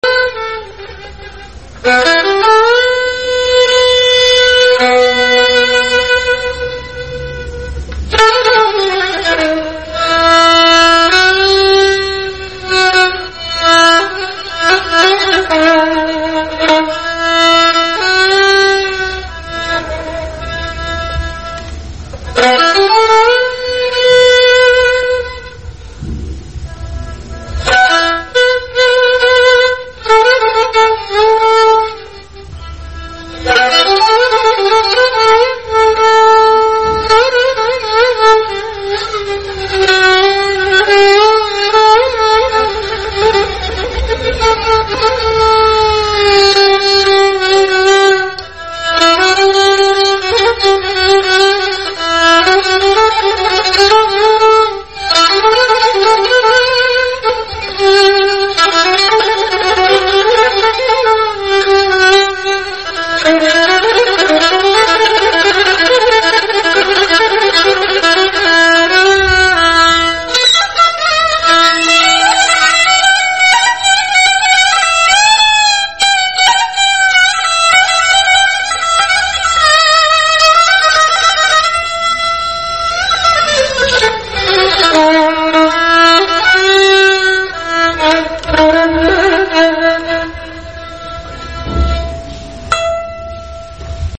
Kamança
Kamança - kamanla çalınan simli musiqi alətidir.